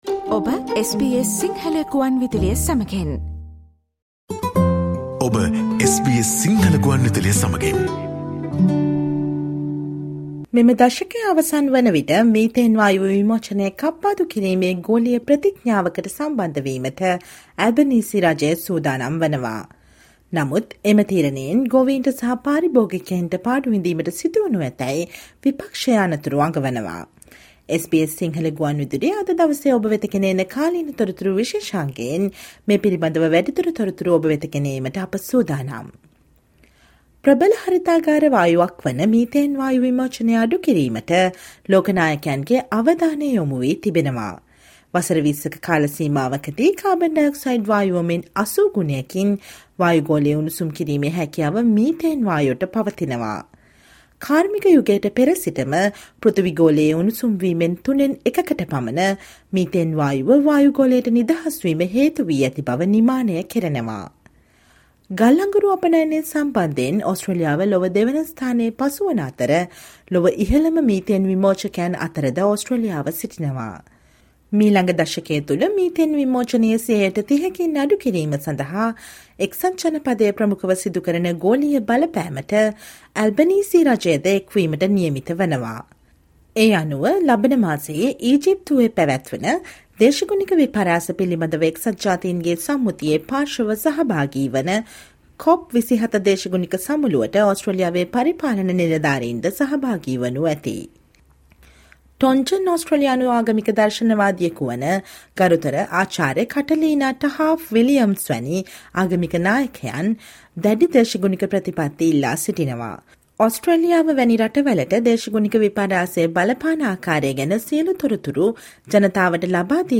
The Albanese government is set to join a global pledge to slash methane emissions by the end of this decade. But the Opposition is warning that decision could cost farmers and consumers. Herewith bringing you more details through our daily current affair radio feature.Listen to the SBS Sinhala Radio's current affairs feature broadcast on Friday 14 October.